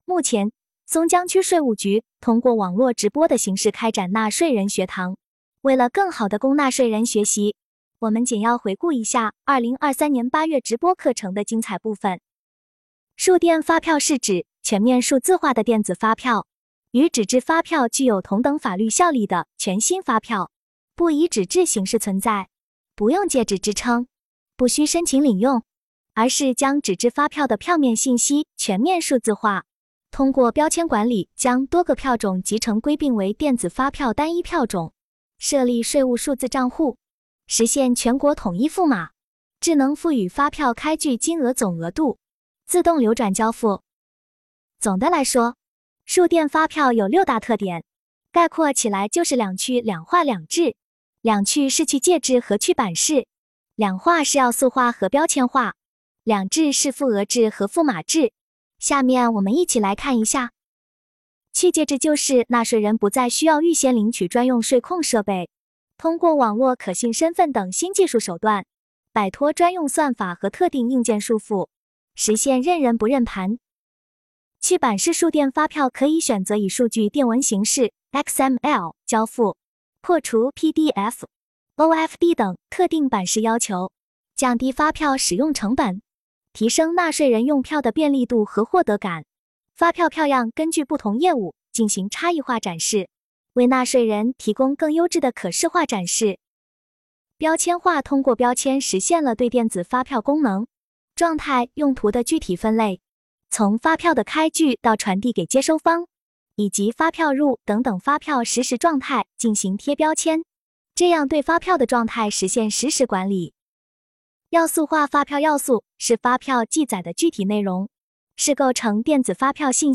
目前，松江区税务局通过网络直播的形式开展了纳税人学堂。